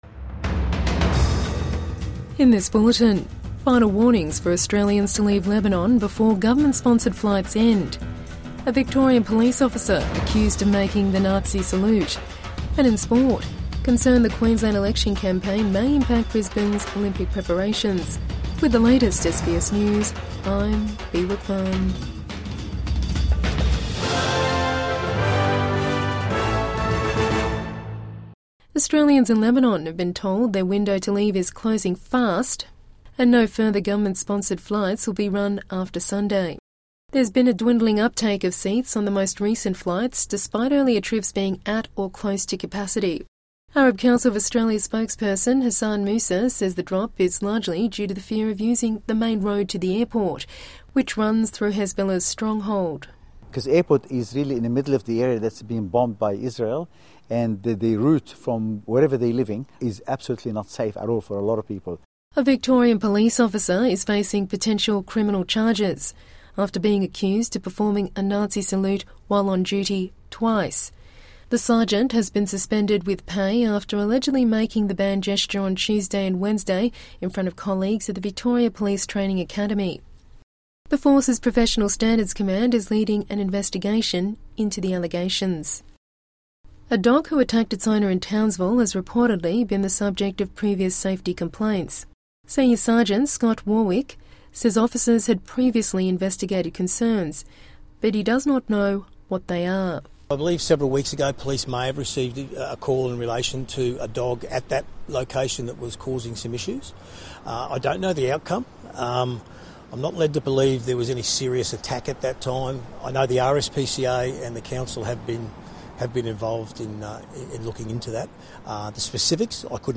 Midday News Bulletin 12 October 2024